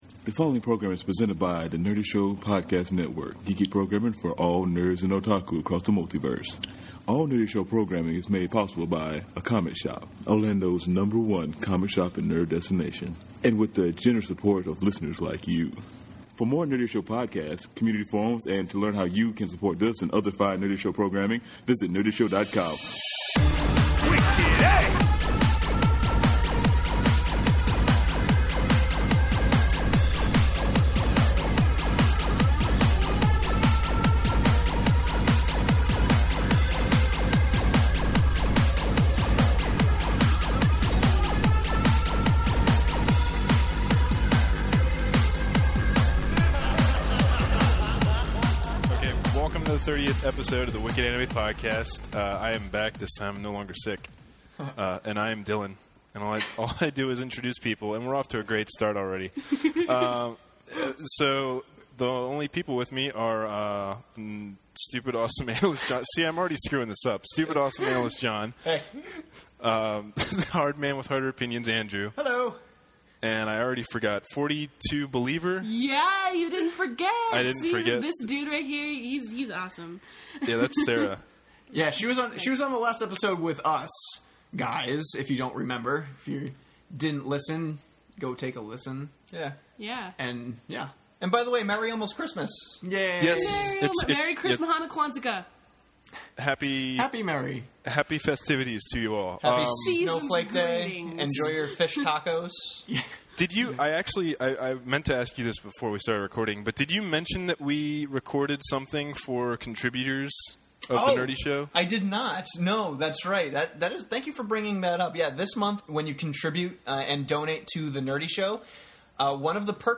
PLUS – Our Christmas gift to you all, be sure to stay til the end to listen to an extended gag reel a full 5 minutes!